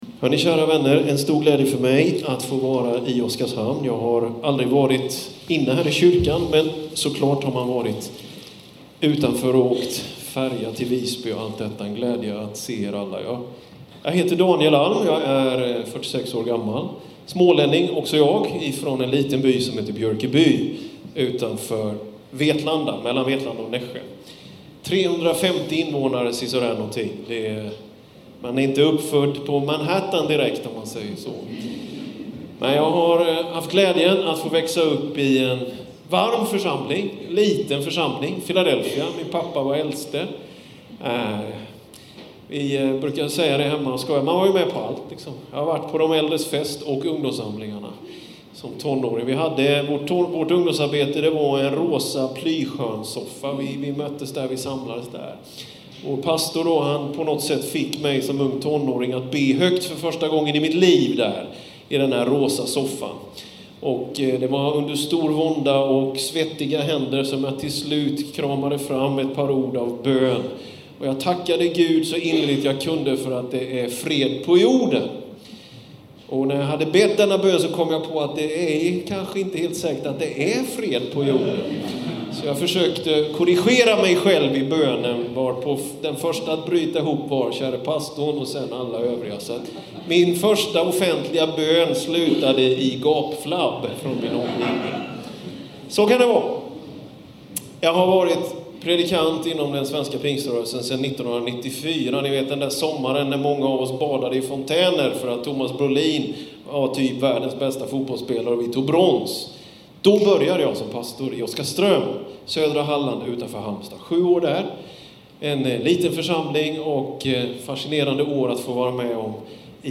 2019-04-07: Bibelsöndag. Lyssna på Gudstjänsten från ”Mediasidan”